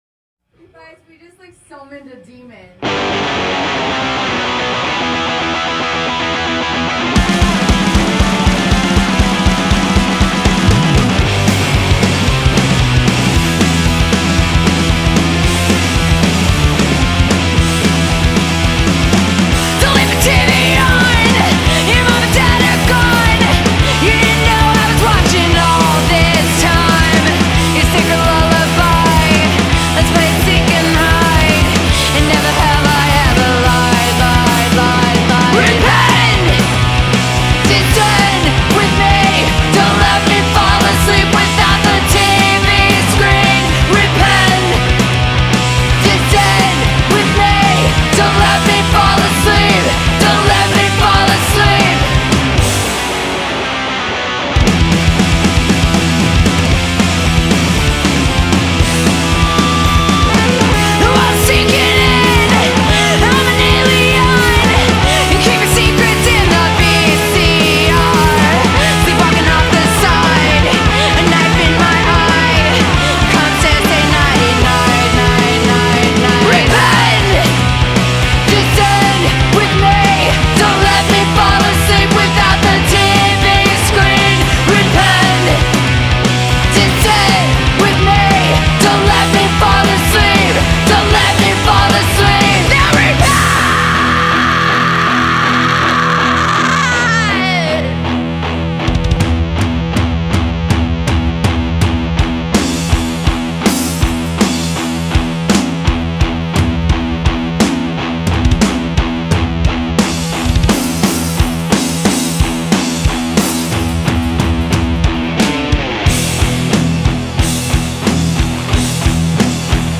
Vocals
Guitars
Bass
Drums